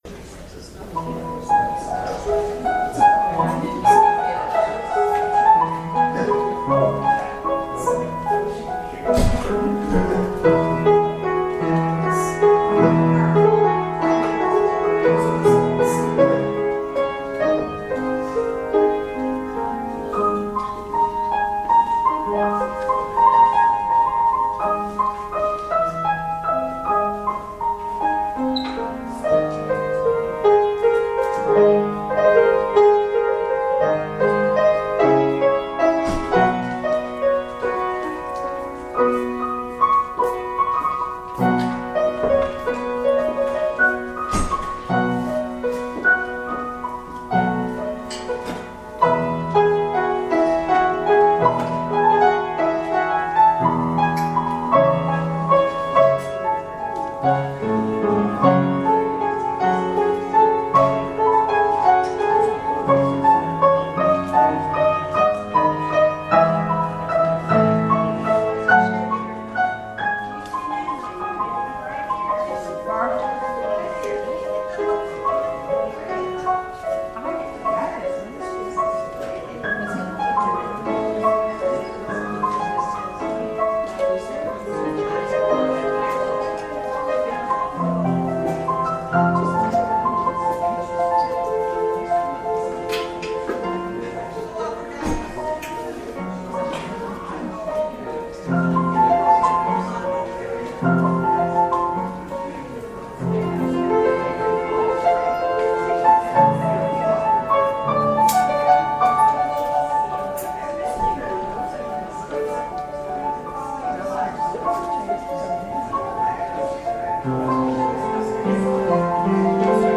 Audio recording of the 10am hybrid/streamed service (in the Parish Hall)
We have been worshiping in the Parish Hall, which doesn’t have the same recording capabilities.